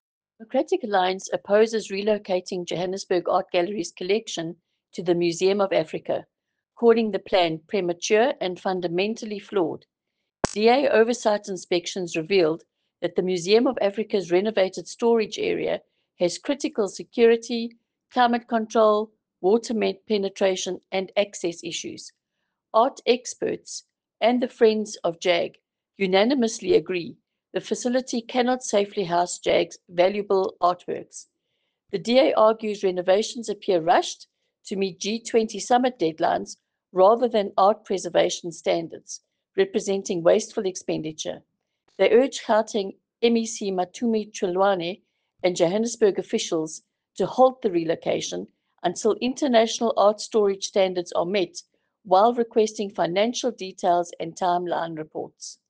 Afrikaans soundbites by Leanne De Jager MPL.